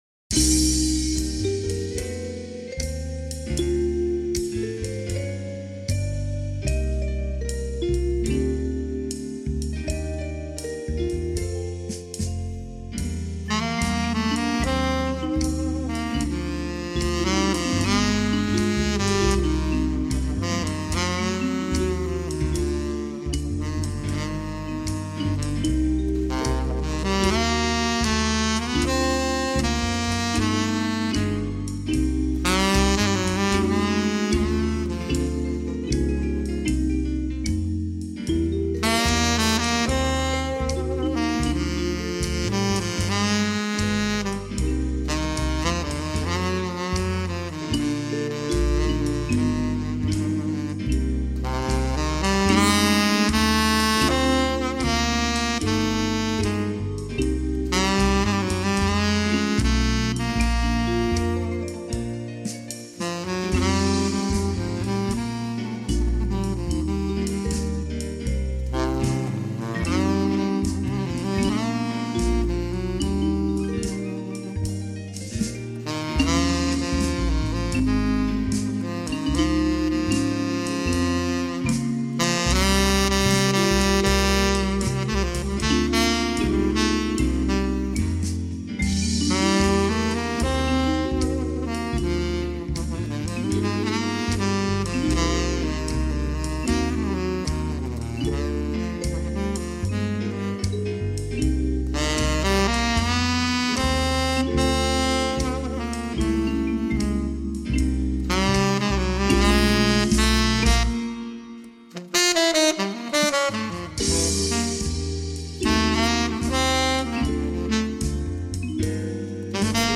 downloadable music for clarinet, saxophone and ensembles